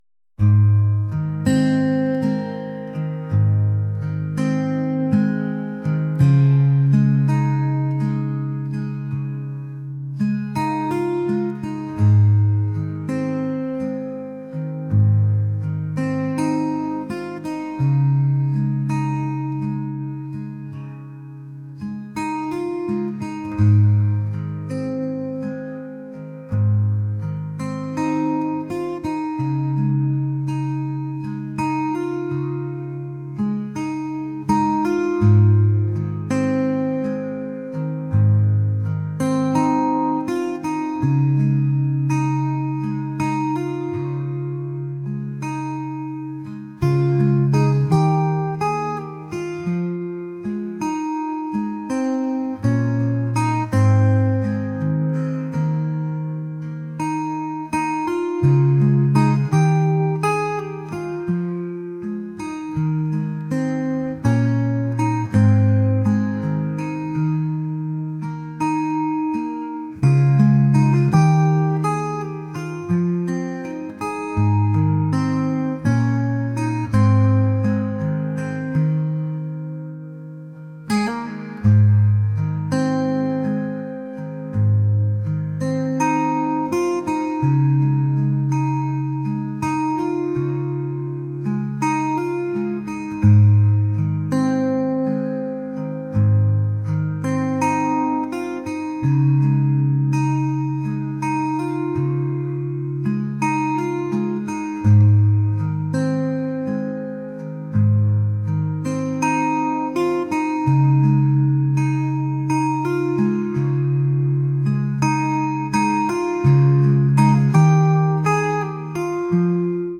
ambient | acoustic | pop